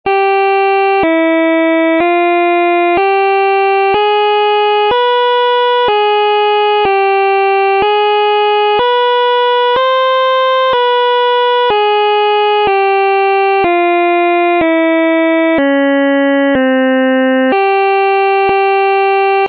Κλίμακα
Οἱ ἤχοι ἔχουν παραχθεῖ μὲ ὑπολογιστὴ μὲ ὑπέρθεση ἀρμονικῶν.